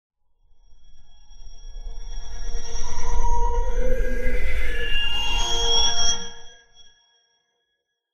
Teleportation Warp or portal effects "Glassy swirling portal sound with rising build-up and whoosh"